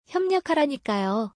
「협력」は「ㄹ（リウル）の鼻音化」により、「ヒョニョ」と発音します。
kyoryokushitekudasaitte1.mp3